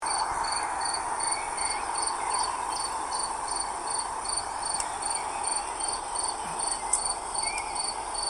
Audioaufnahmen aus dem Schutzgebiet
feldgrille.mp3